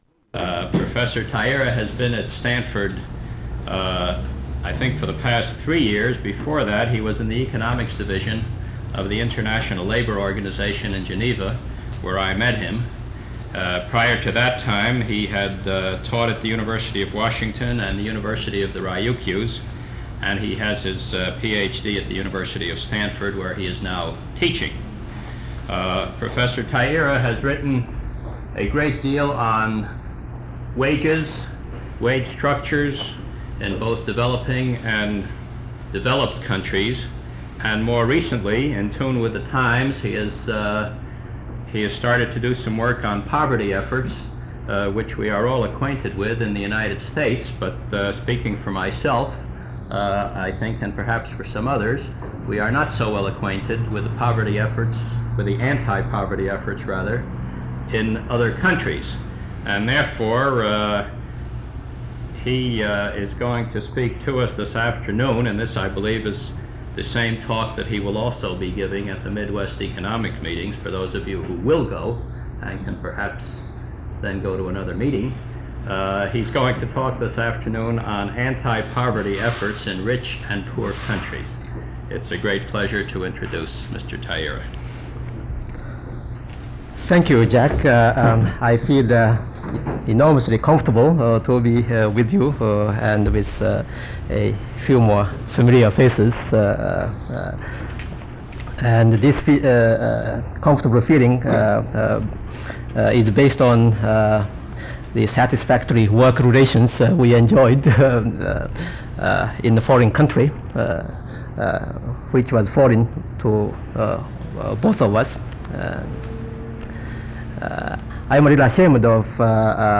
Original Format: Open reel audio tape